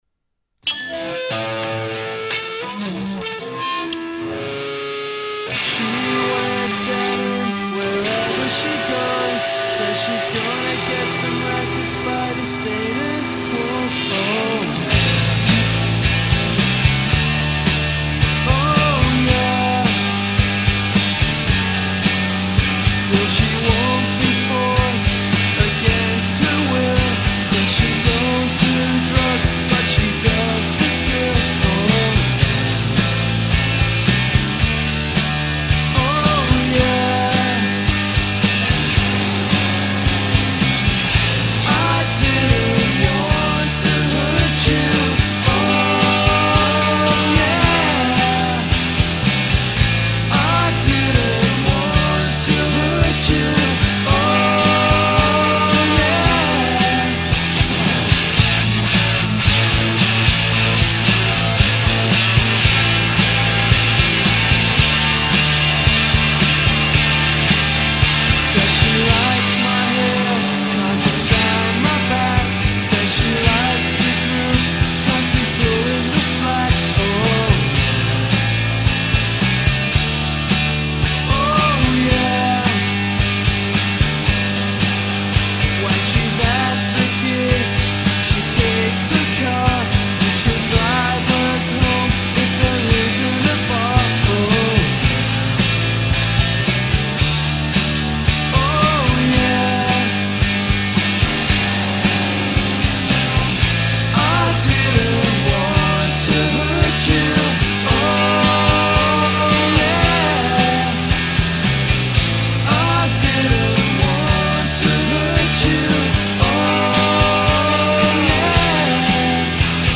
The film is heavy on 90s nostalgia rock.
Especially, when it comes to the guitar noodling.